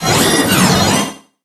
Cri de Regieleki dans Pokémon HOME.